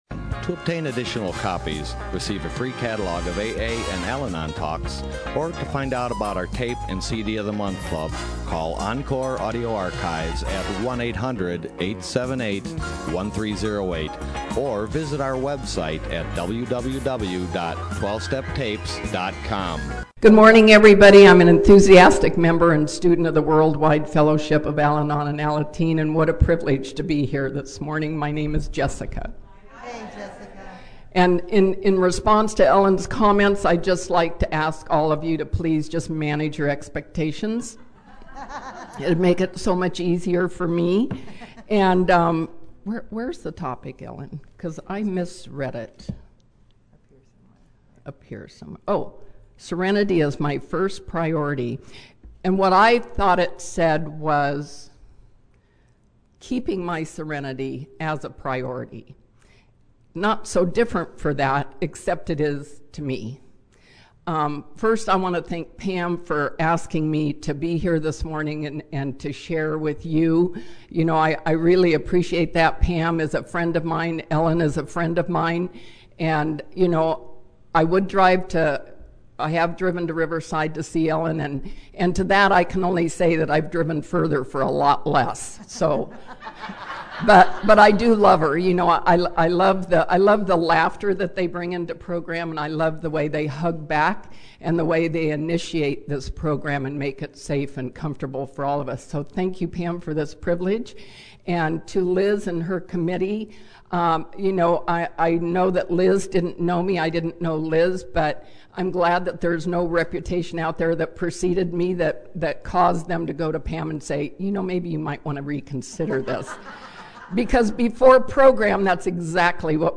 Orange County AA Convention 2014